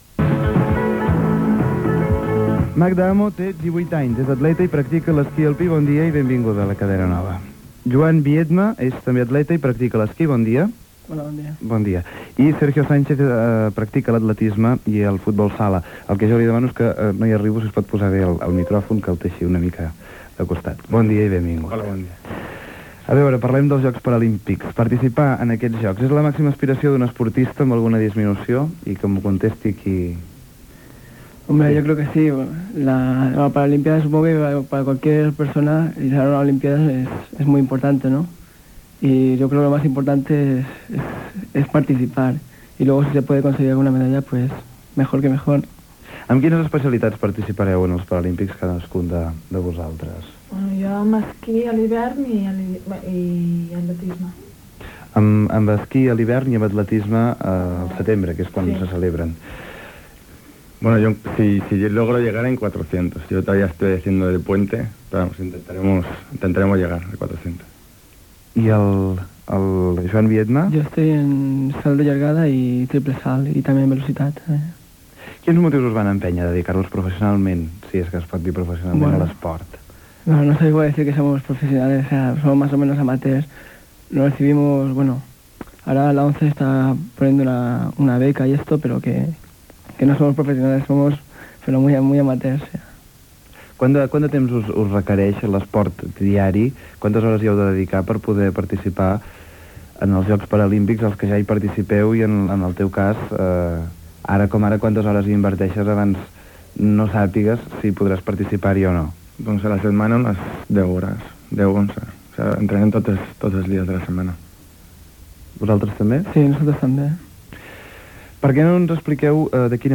Els Jocs Paralímpics de Barcelona amb una entrevista a tres atletes
FM